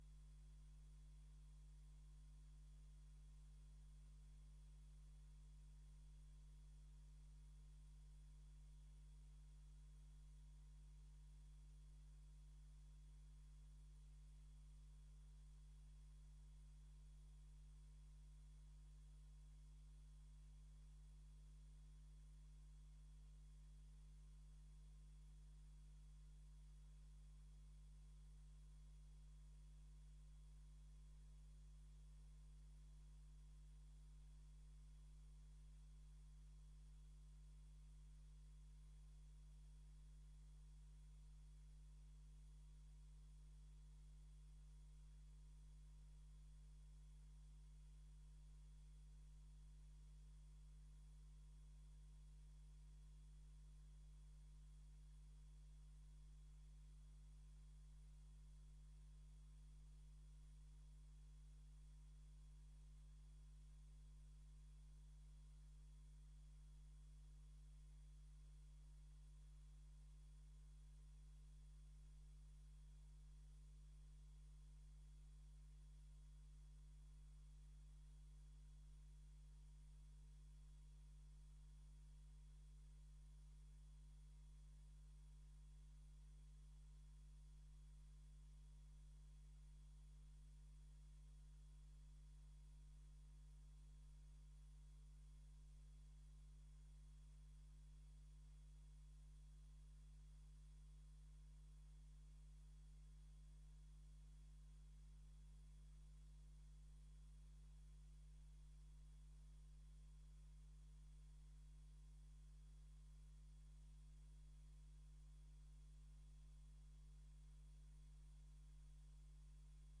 Raadscommissie 16 september 2024 19:30:00, Gemeente Dalfsen
Download de volledige audio van deze vergadering